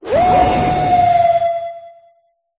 zacian_ambient.ogg